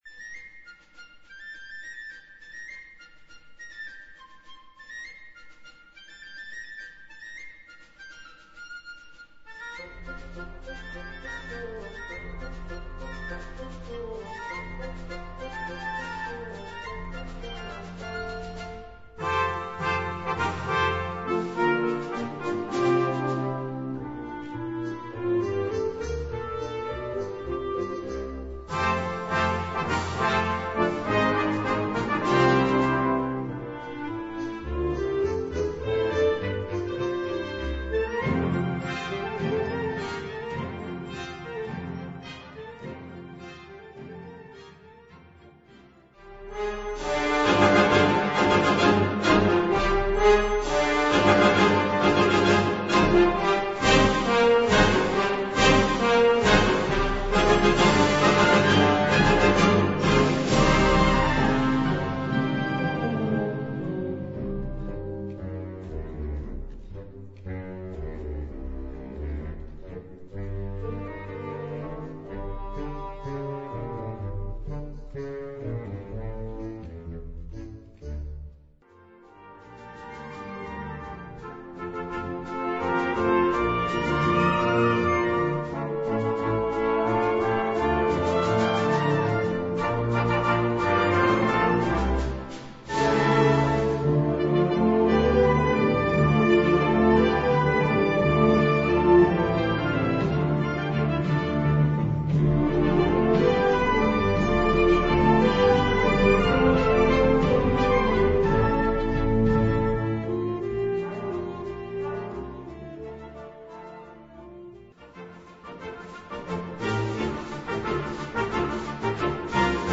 Catégorie Harmonie/Fanfare/Brass-band
Sous-catégorie Musique contemporaine (1945-présent)
Instrumentation Ha (orchestre d'harmonie); CB (Concertband)
Le tout s'achève sur un final grandiose.